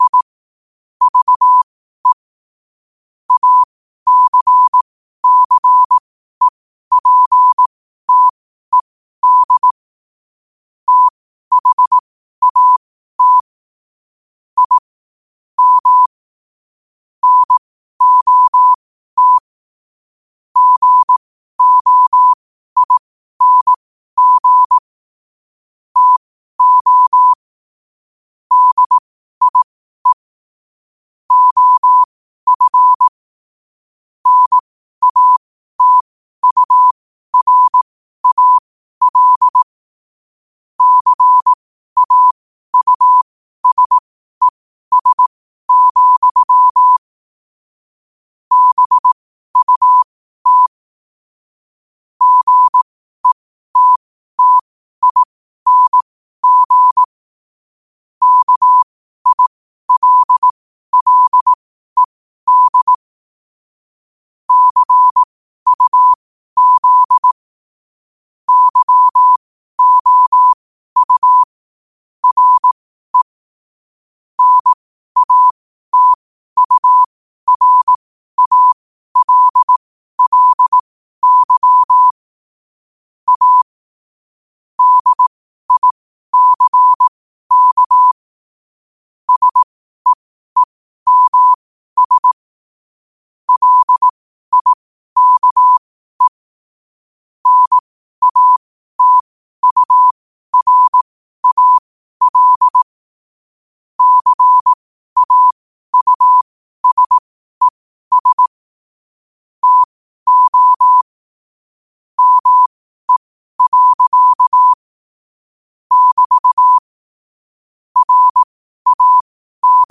Quotes for Sun, 27 Apr 2025 in Morse Code at 12 words per minute.